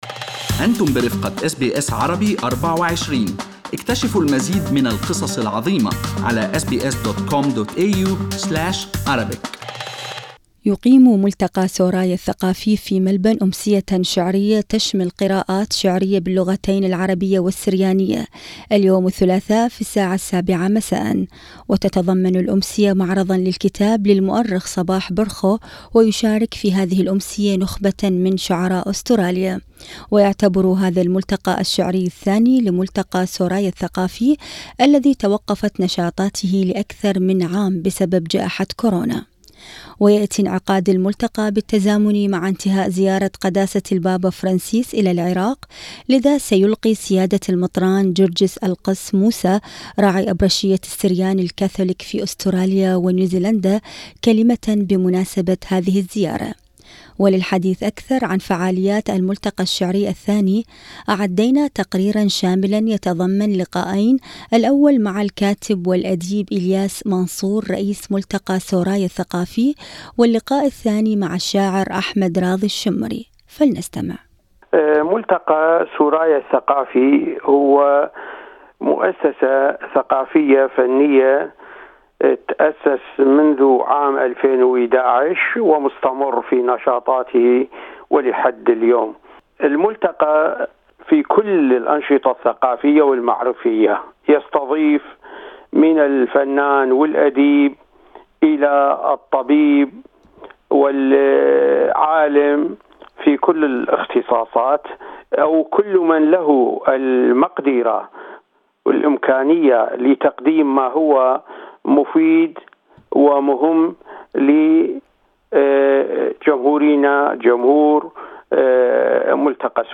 أعدينا تقريرا شاملا يتضمن لقاءين